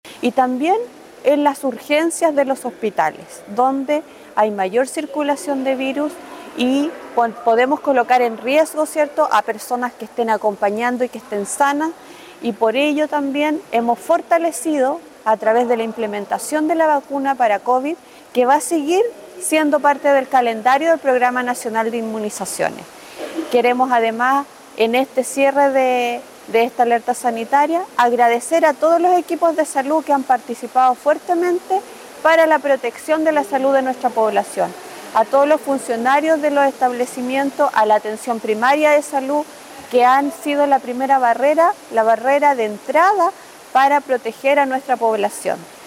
La autoridad sanitaria también enfatizó que se continuará fortaleciendo la prevención, mediante las vacunas contra el SarsCov-2 que ya forma parte del calendario nacional de inmunización.